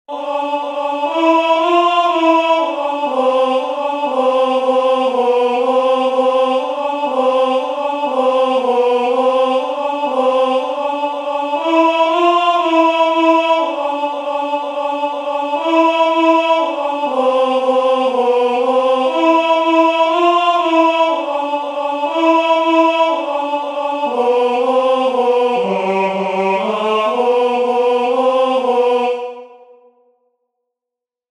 "Dum lucem habetis," the first responsory verse from the first nocturn of Matins, Common of Apostles